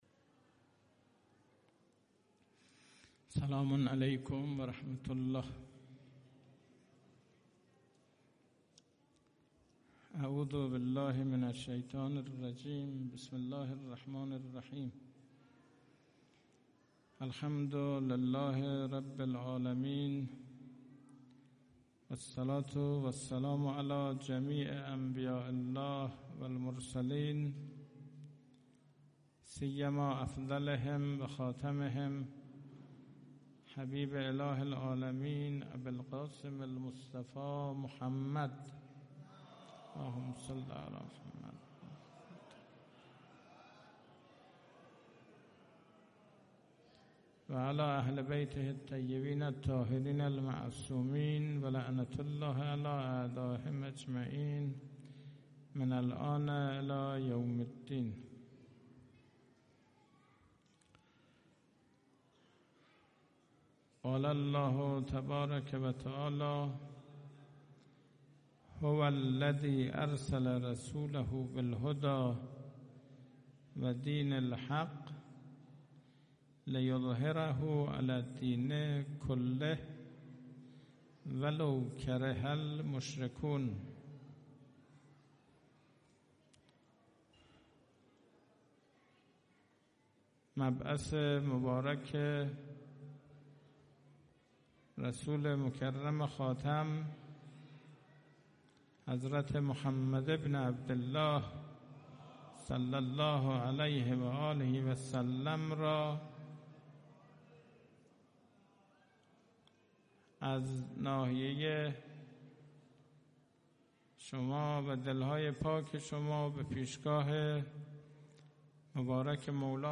- سخنرانی شب مبعث پیامبر اسلام صلی‌الله‌علیه‌وآله